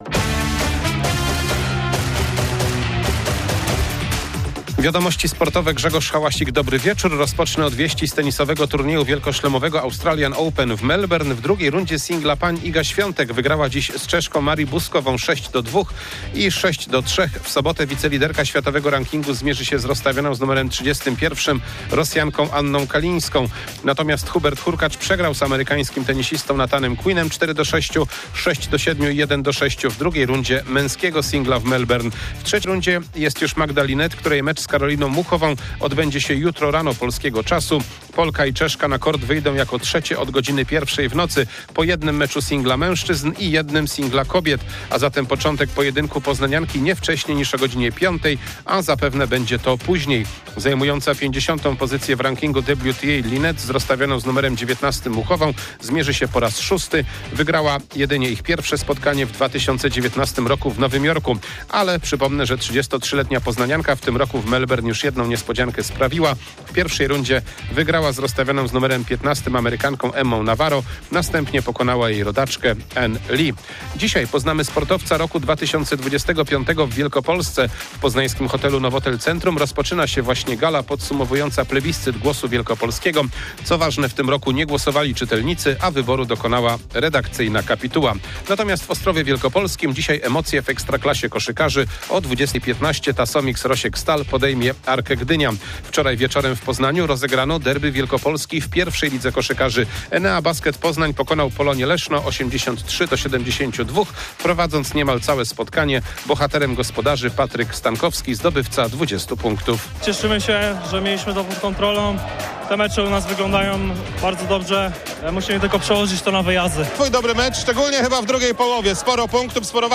22.01.2026 SERWIS SPORTOWY GODZ. 19:05